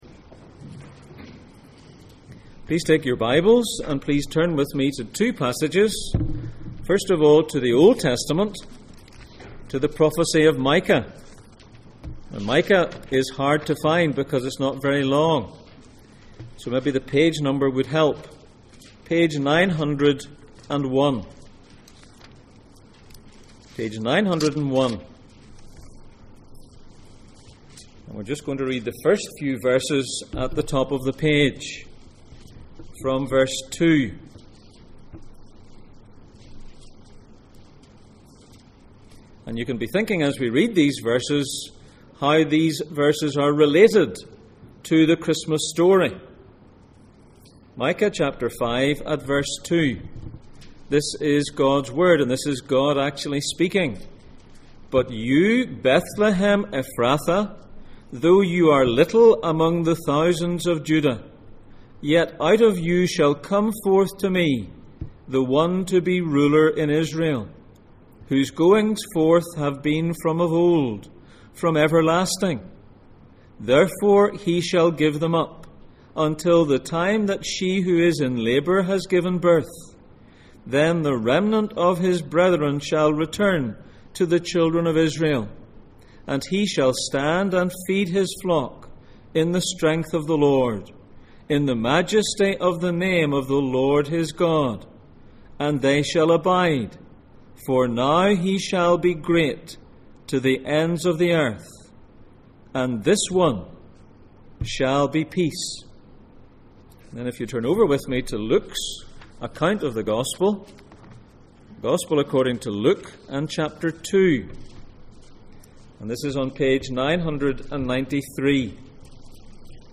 God sends a Saviour Passage: Micah 5:2-5, Luke 2:1-7 Service Type: Sunday Morning %todo_render% « An angel brings good news Living is Christ